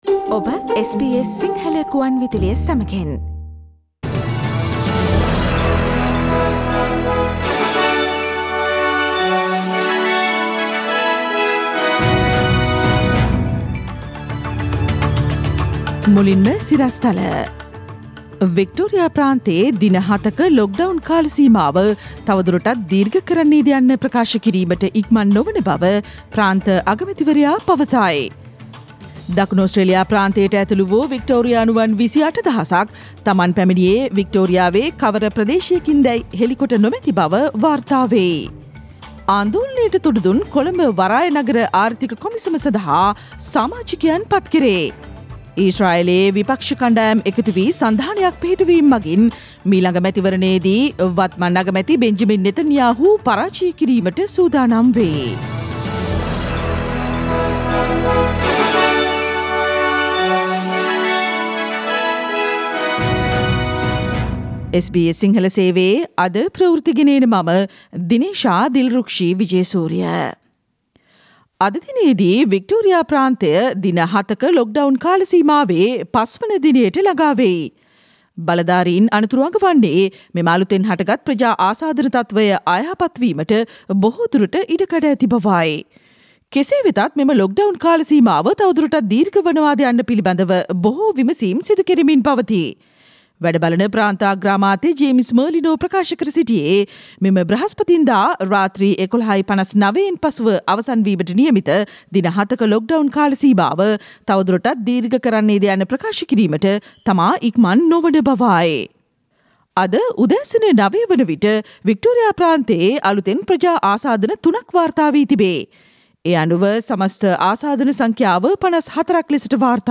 Listen to the latest news from Australia, Sri Lanka, across the globe and the latest news from sports world on SBS Sinhala radio news bulletin – Tuesday 1 June 2021.